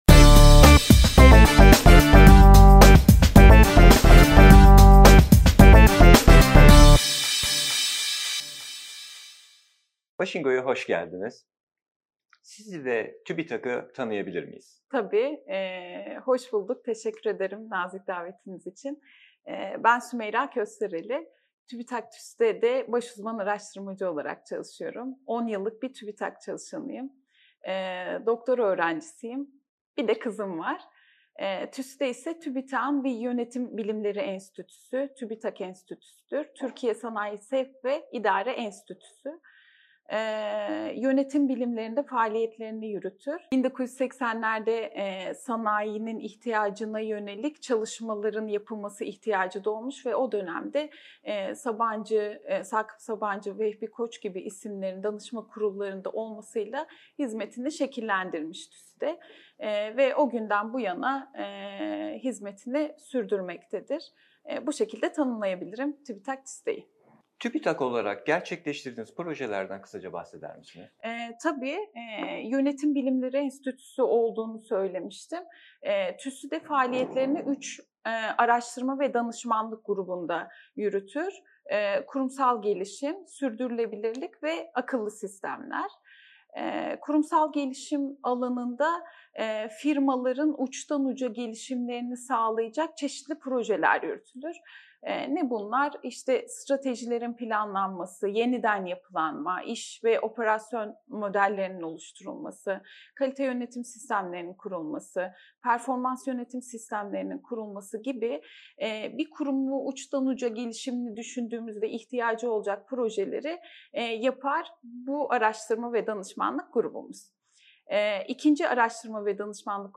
Röportajı